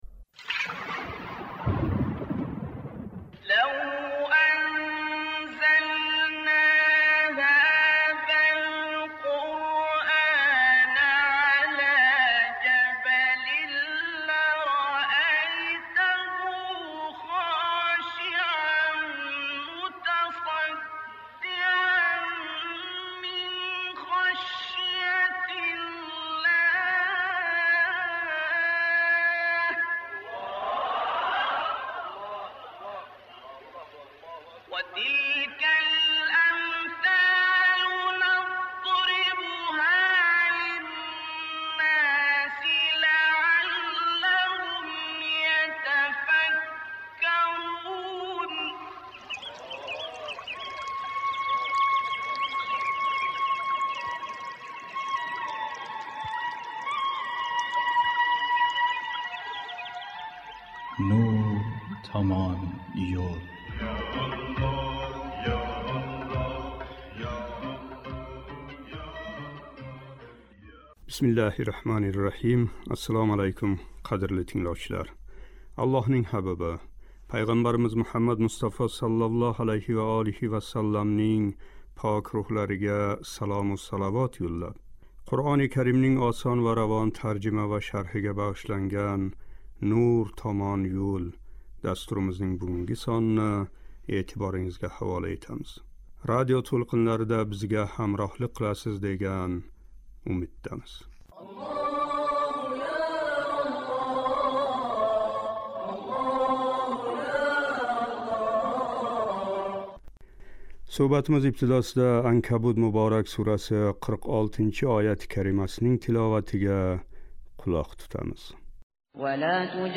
Суҳбатимиз ибтидосида “Анкабут” муборак сураси 41-42--ояти карималарининг тиловатига қулоқ тутамиз.